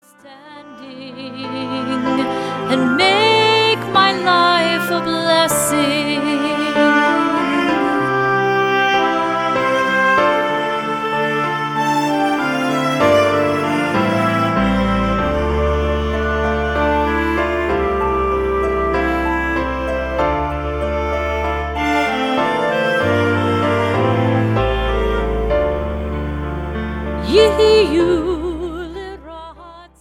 contemporary Shabbat music